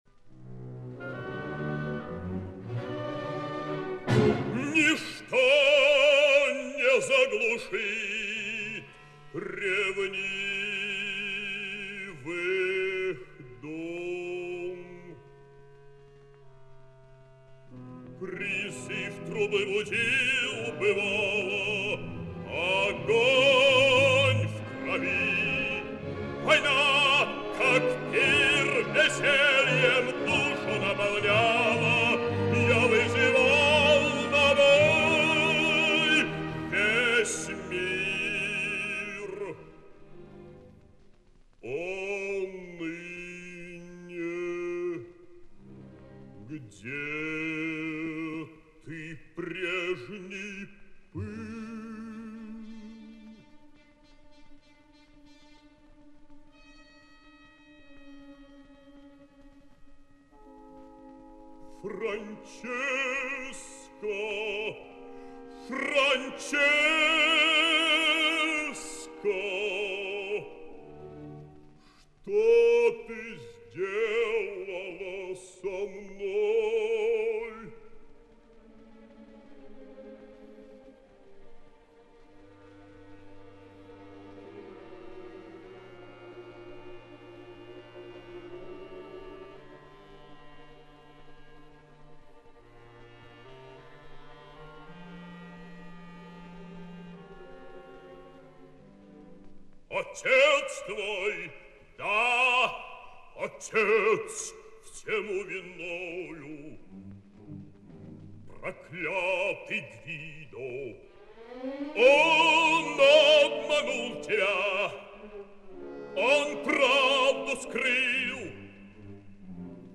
òpera en dos escenes, pròleg i epíleg